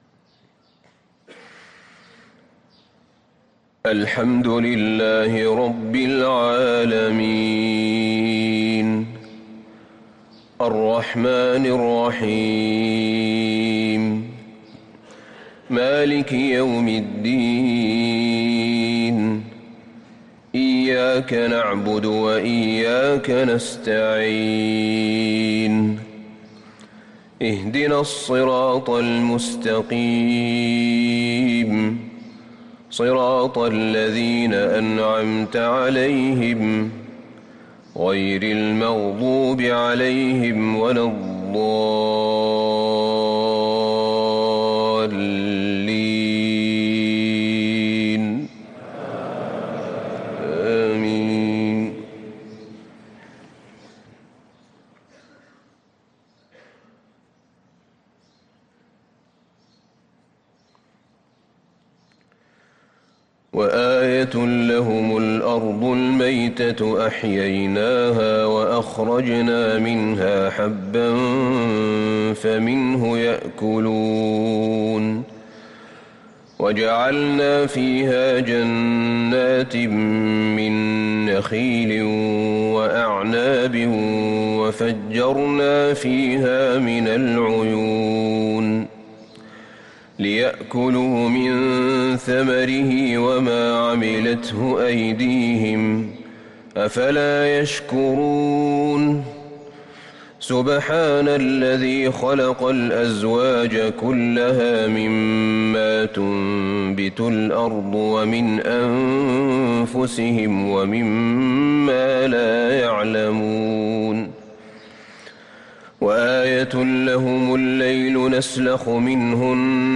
فجر الاثنين 10 محرم 1444هـ آخر سورة يس | Fajr prayer from Surah yasen 8-8-2022 > 1444 🕌 > الفروض - تلاوات الحرمين